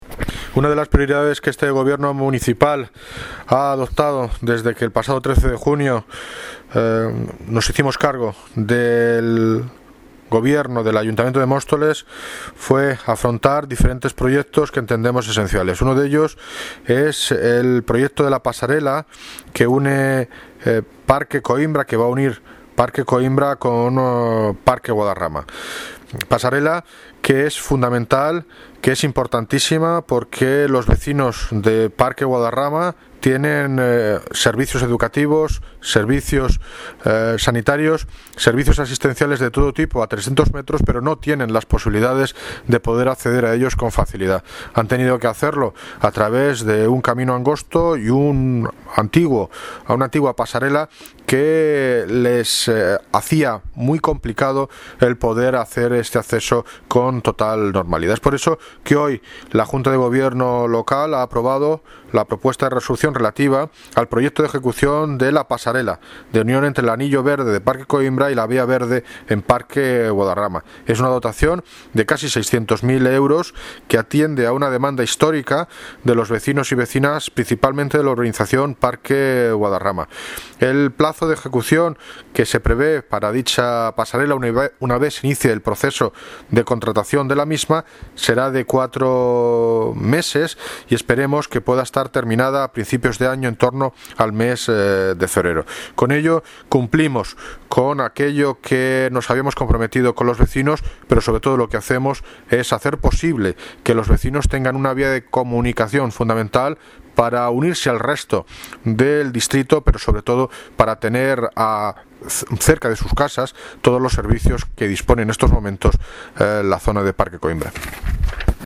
Audio - David Lucas (Alcalde de Móstoles) Sobre pasarela Parque Guadarrama